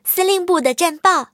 M22蝉查看战绩语音.OGG